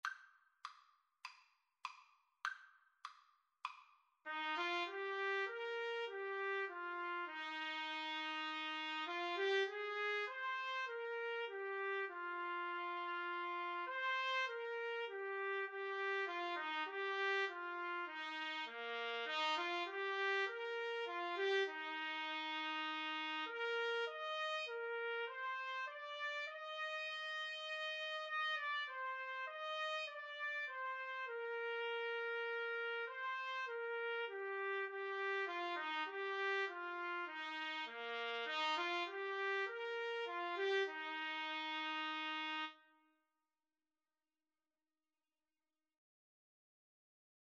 4/4 (View more 4/4 Music)
Classical (View more Classical Trumpet Duet Music)